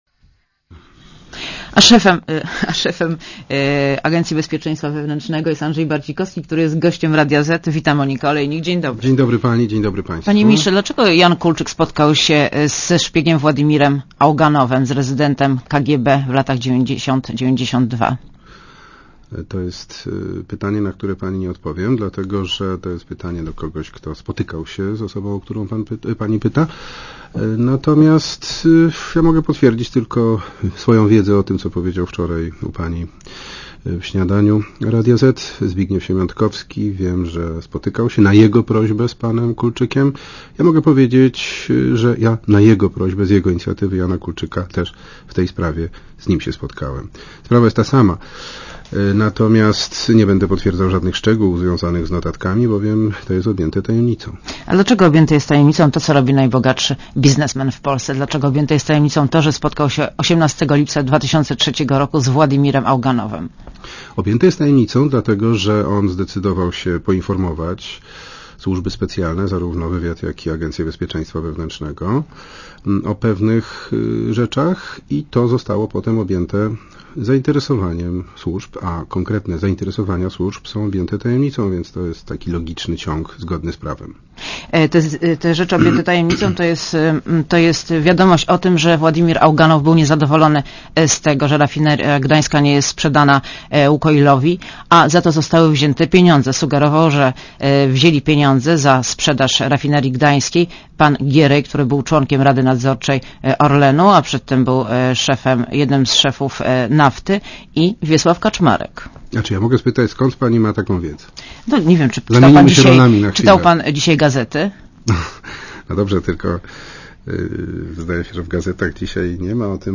* Posłuchaj wywiadu * Gościem Radia Zet jest szef ABW , Andrzej Barcikowski.